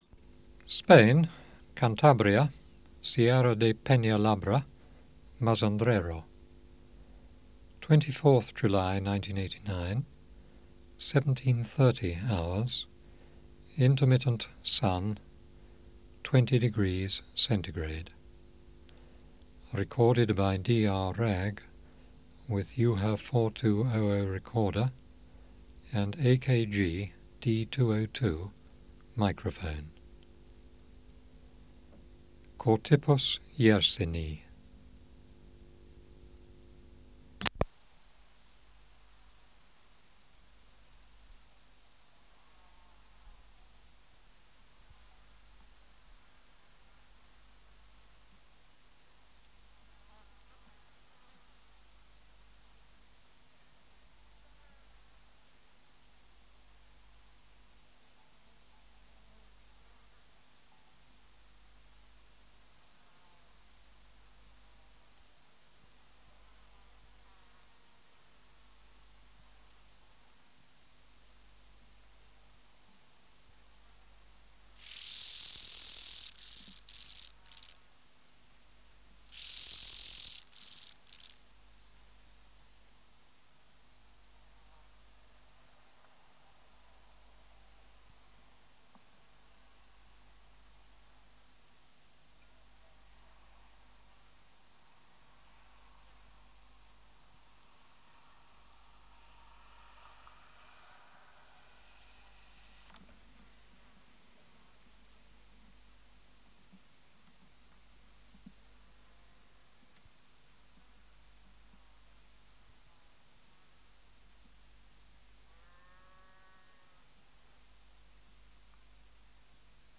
Natural History Museum Sound Archive Species: Chorthippus (Glyptobothrus) yersini
Air Movement: Intermittent light breeze
Substrate/Cage: On grass
Microphone & Power Supply: AKG D202 (LF circuit off) Distance from Subject (cm): 10 Windshield: AKG W10